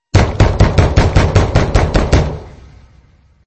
Стук в дверь